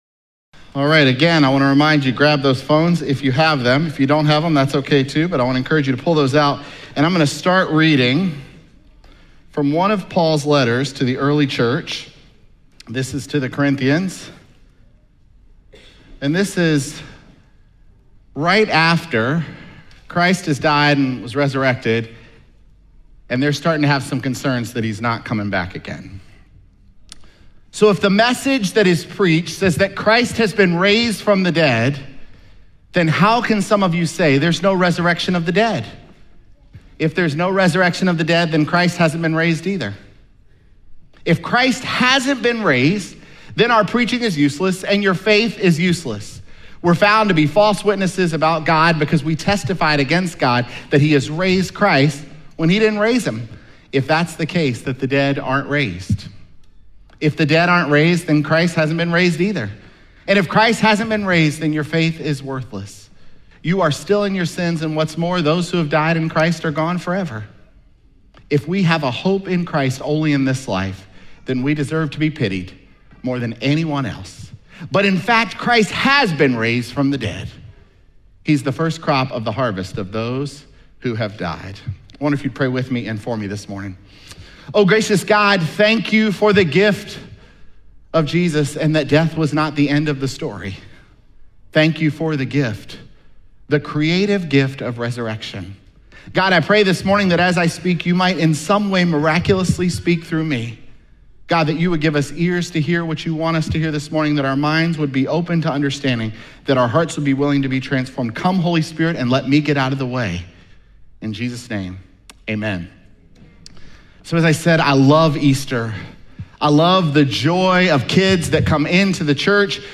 Sermons
Apr20SermonPodcast.mp3